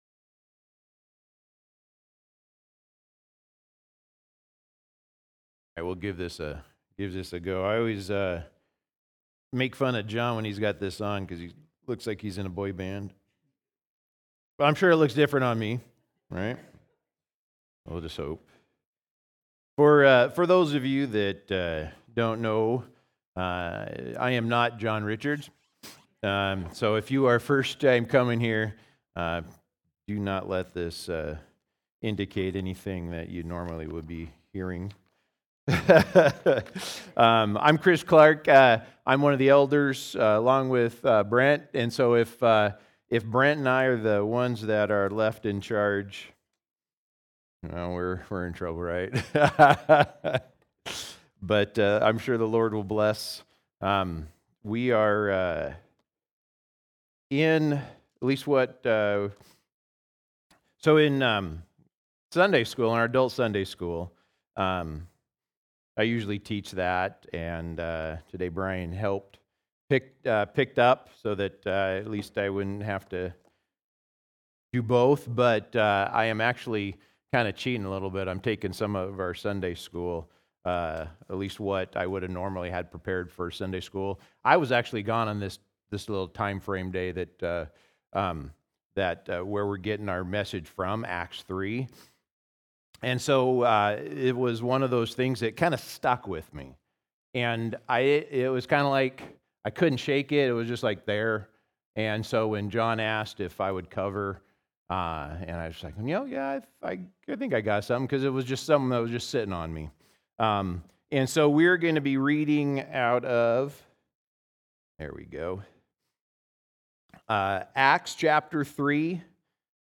Passage: Acts 3:1-10 Service Type: Sunday Morning